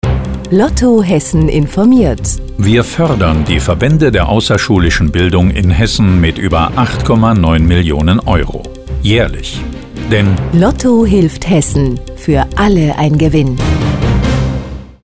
Profisprecherin.
Sprechprobe: Industrie (Muttersprache):
german female voice over artist